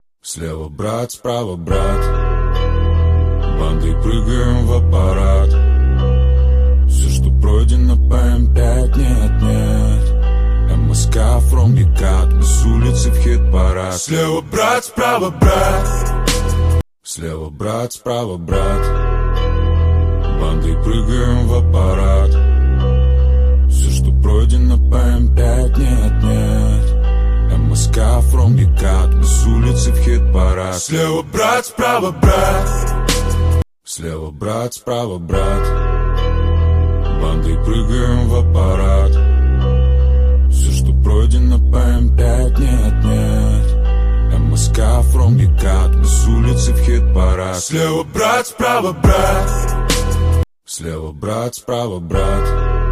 • Жанр: Русская музыка